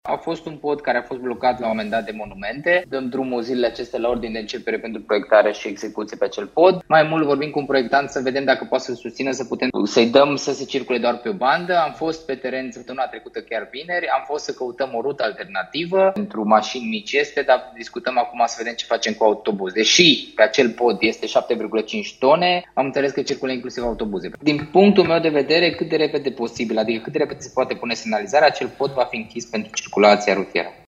Structura de rezistență a crăpat și în orice moment se poate întâmpla o tragedie, spune vicepreședintele Consiliului Județean Timiș, Cristian Moș: